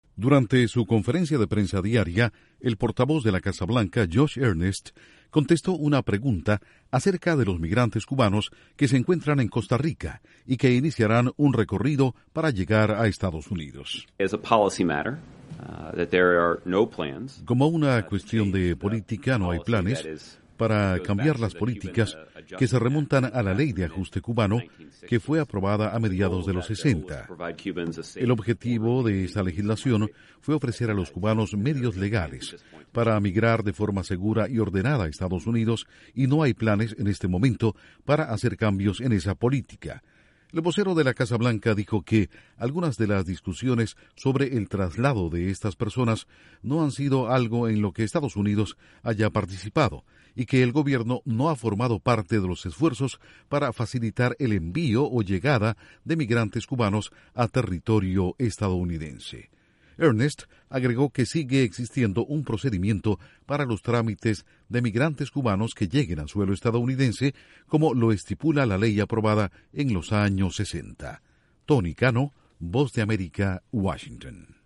La Casa Blanca al tanto de informes sobre los cubanos migrantes que se encuentran en Costa Rica y que viajarán a Estados Unidos. Informa desde la Voz de América en Washington